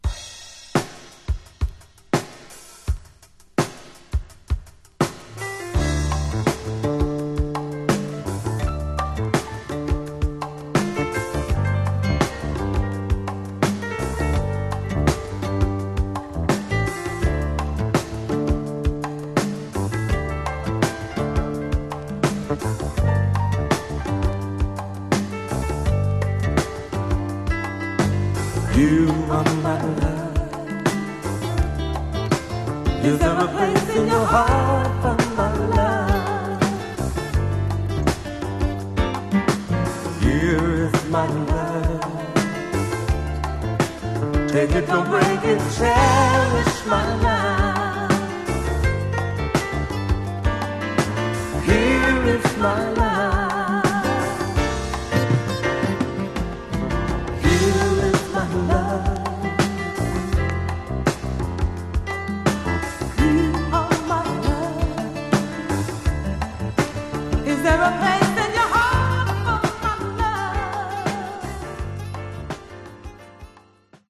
Genre: Modern Soul